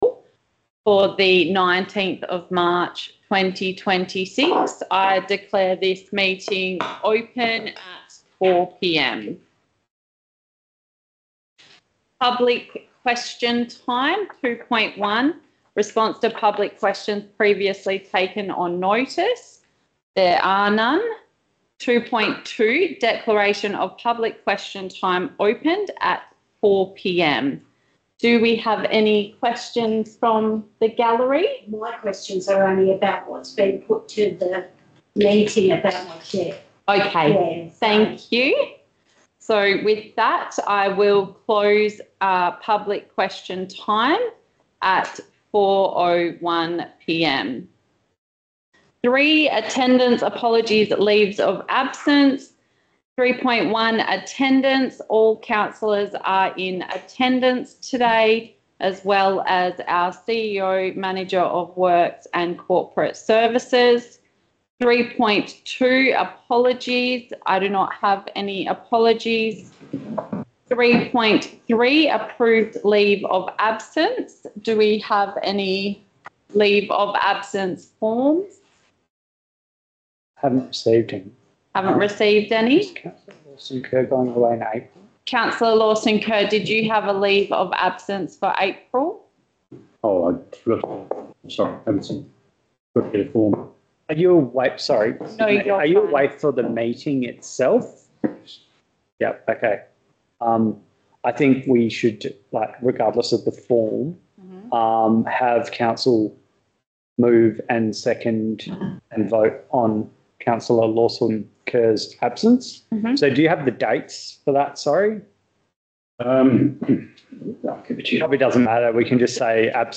Ordinary Council Meeting » Shire of Wyalkatchem
Location: Shire of Wyalkatchem Council Chambers